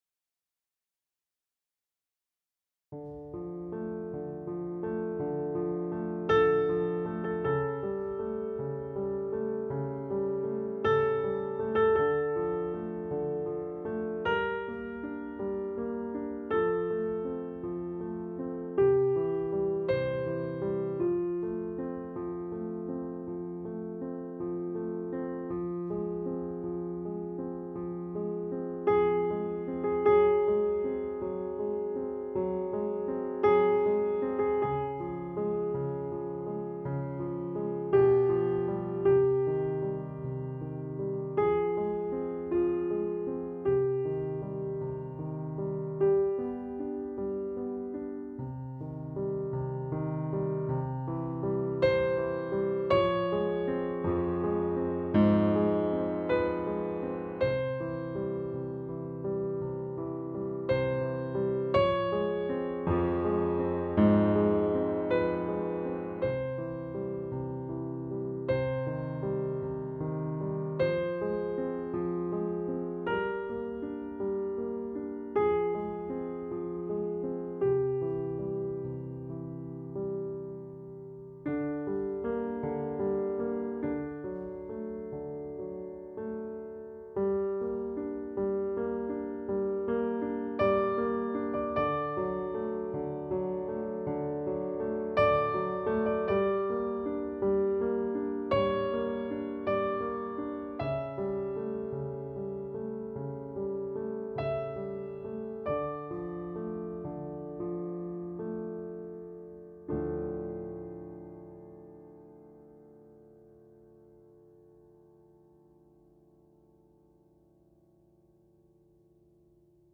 Piano
Moonlight Sonata - D minor - MP3